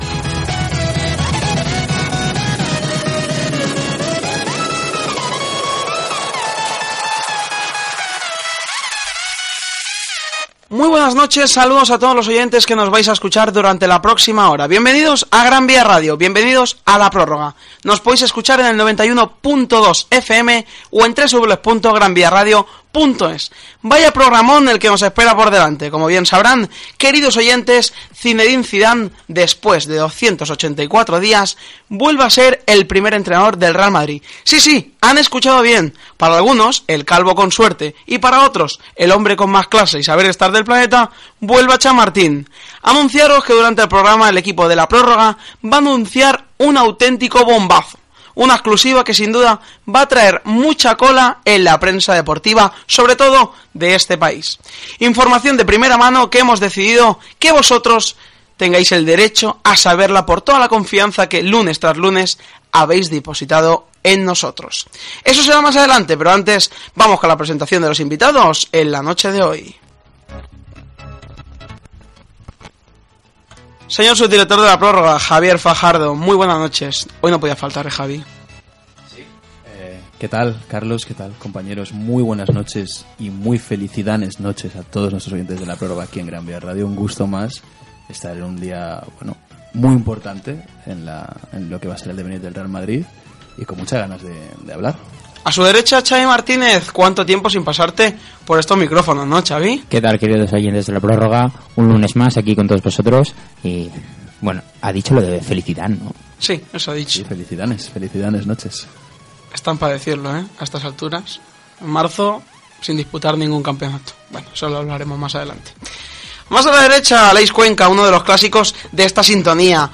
Zinédine Zidane nou entrenador del Reial Madrid. Gènere radiofònic Esportiu